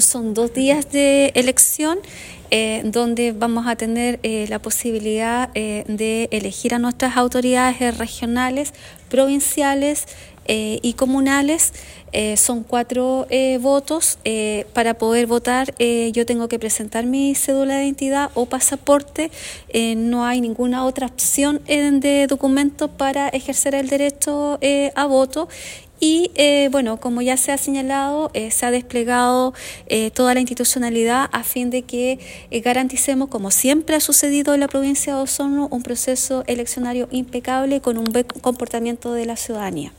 La Delegada Presidencial Claudia Pailalef, destacó la importancia de contar con establecimientos adecuados para facilitar el ejercicio democrático de los ciudadanos, y señaló que se están tomando todas las precauciones necesarias para que la votación se desarrolle de manera segura y eficiente.